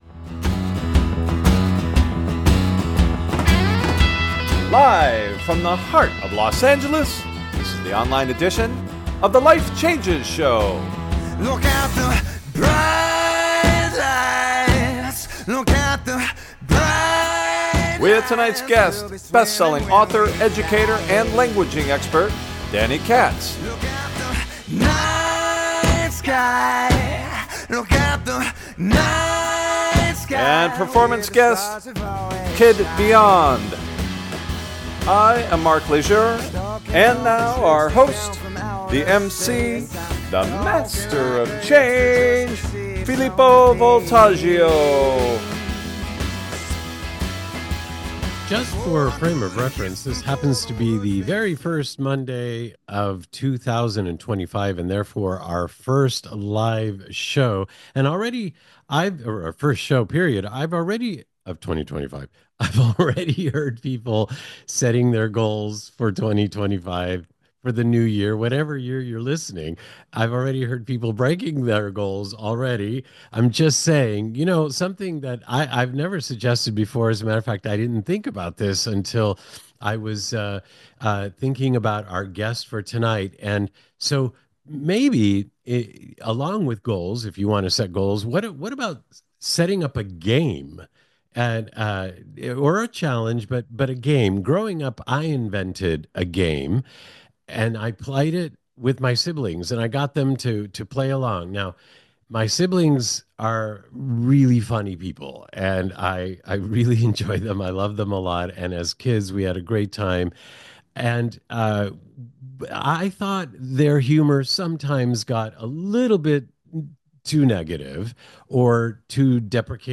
Talk Show Episode, Audio Podcast
Featuring Interview Guest